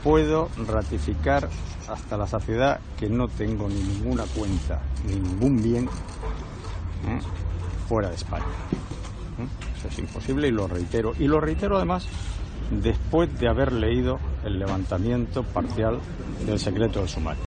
Zaplana ha atendido a los periodistas tras acudir por segundo lunes consecutivo a firmar en el juzgado de guardia de Valencia.